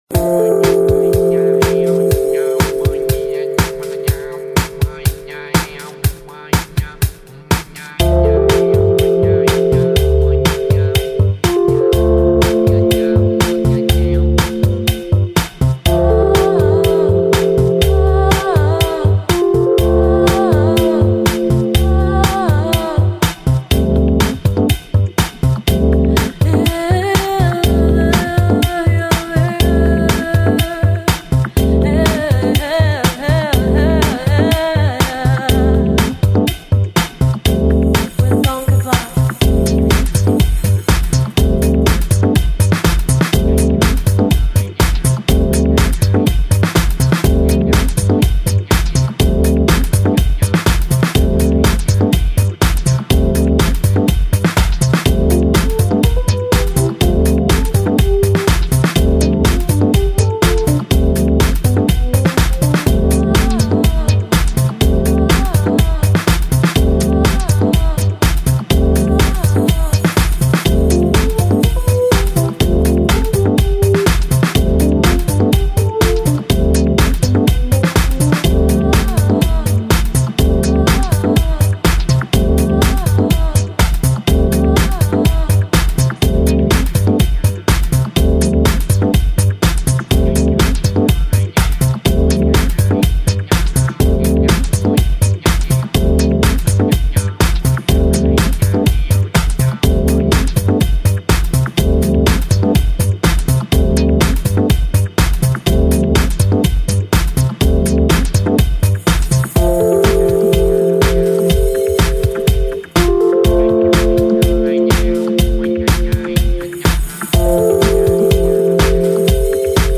甜美中氤蕴迷幻，舒适解压的聆听旅程。
Bossa Nova，Downtempo，Nu Jazz 与 Lounge